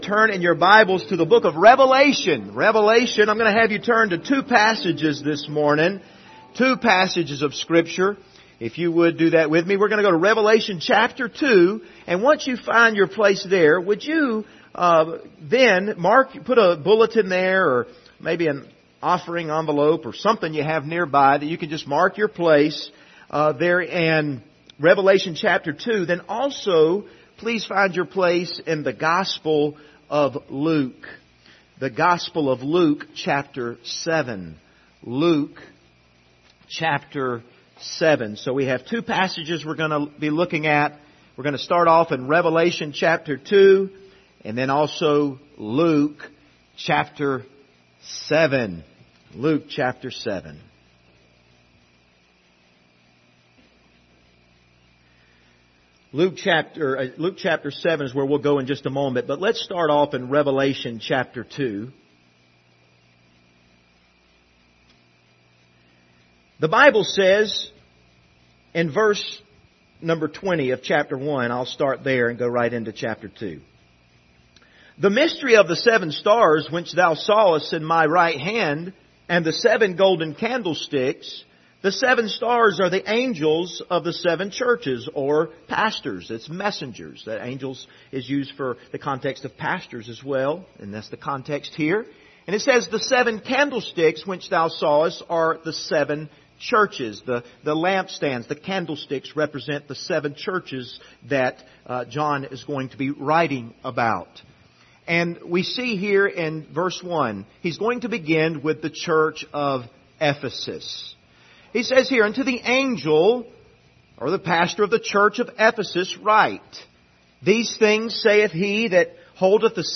Passage: Revelation 1:20-2:7 Service Type: Sunday Morning View the video on Facebook Topics